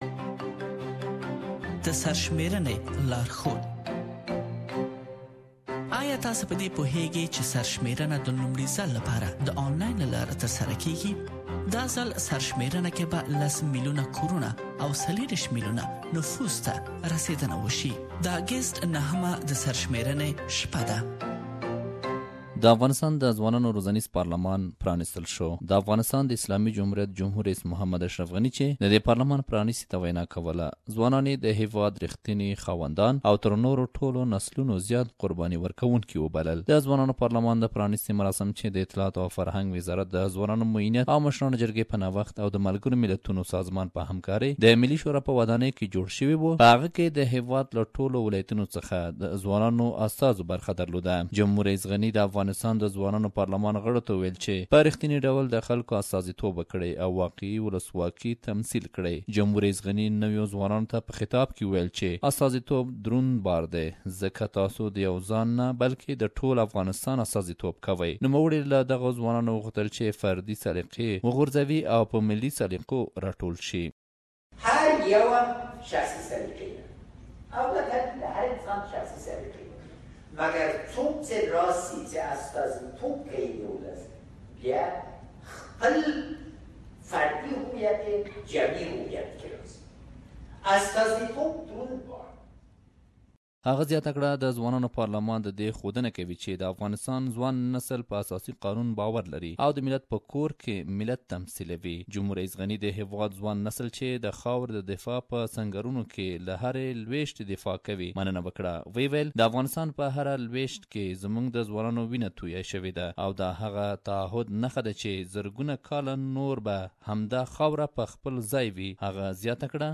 Some civil society members say that government officials have interfered with the election and they were selecting those candidates who were close to them. We have prepared a report that you can listen to it here.